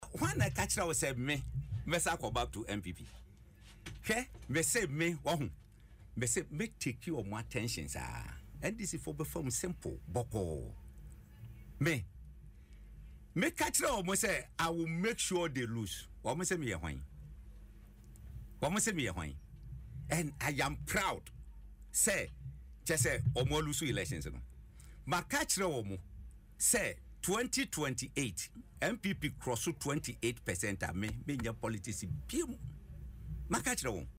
The tough spoken politician made the bold declaration on Adom FM Dwaso Nsem, stating he will never return to the NPP.